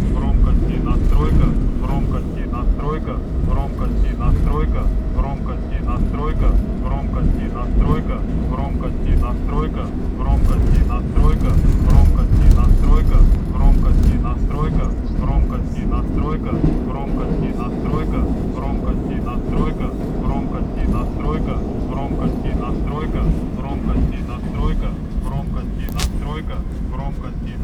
В общем, по дороге от Электрозаводской до Арбатской, а это примерно минут 10-15, заело громкую связь.
Если быть точным то это звучало как-то хромкости настройка, с типичным украинским акцентом. Примерно на Курской это превратилось у меня в голове из «Хромкости настройка» в «Гром кости на стройка», ну а к Площади революции — в «Гром костей на стройке», в общем не плохой DIY Indastrial Noise получился.
ADD: Кстати, записал это все на диктофон, правда громкость не очень, как вытяну звук из айфона — обязательно выложу этот мега хит :)